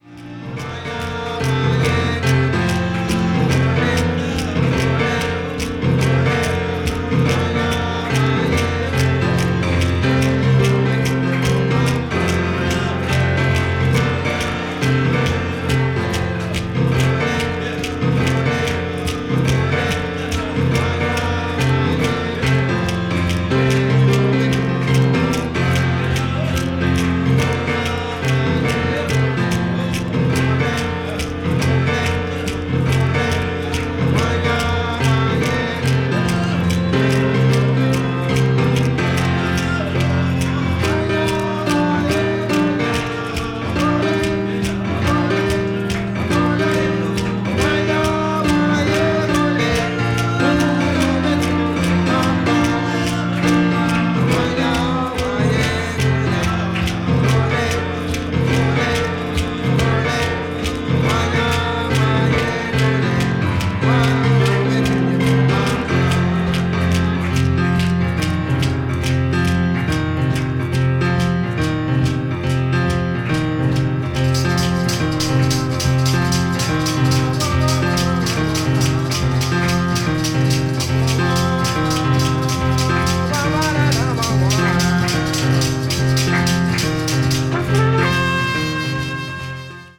media : EX+/EX+(わずかにチリノイズが入るヶ所あり)
contemporary jazz   ethnic jazz   free jazz